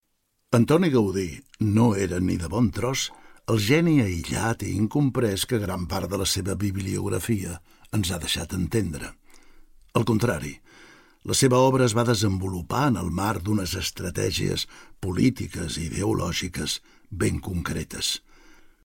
Exposició narrada per l'actor Josep Maria Pou
Amb aquesta exposició, el Museu proposa una experiència inèdita: recórrer la mostra escoltant la narració amb la veu de l'actor Josep Maria Pou. Coneixeràs la trajectòria complexa d'un dels millors arquitectes de la història, l'obra del qual ha quedat lligada de manera indefectible a la ciutat de Barcelona.